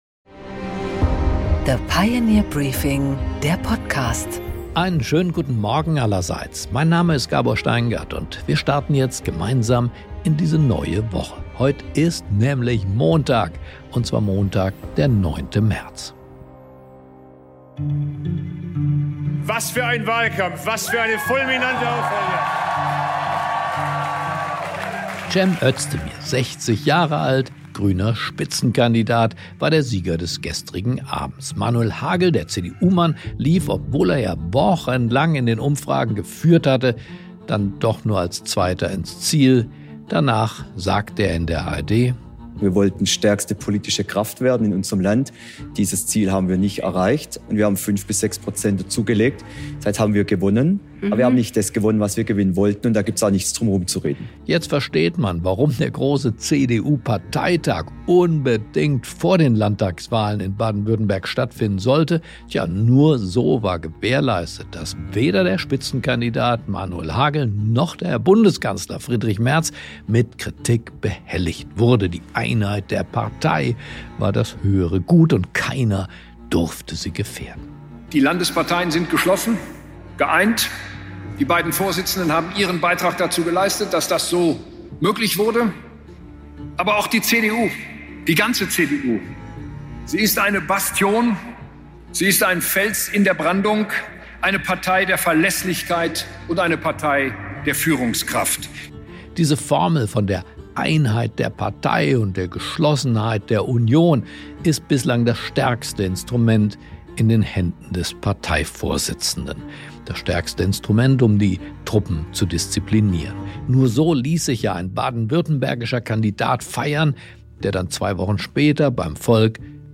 Gabor Steingart präsentiert das Pioneer Briefing.